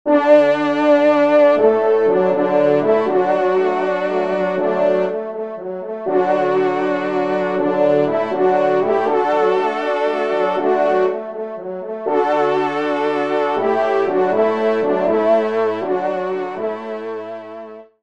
Genre : Fantaisie Liturgique pour quatre trompes
ENSEMBLE